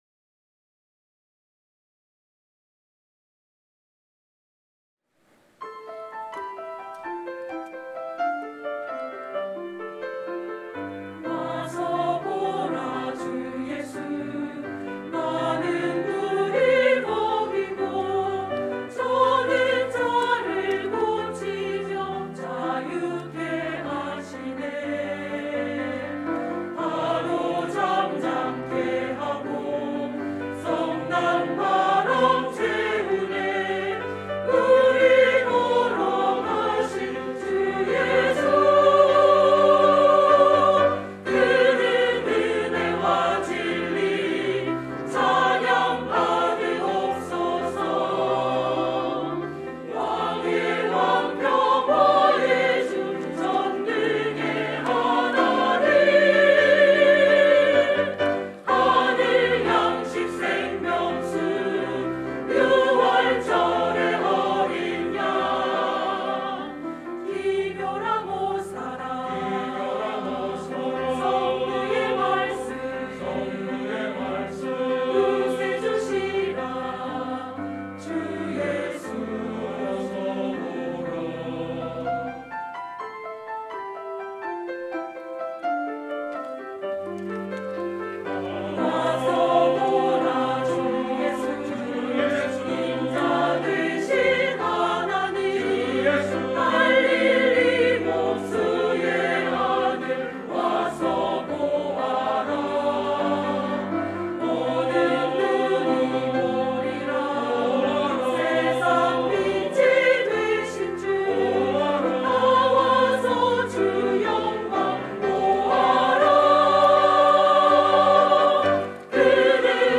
영은 3.3 예배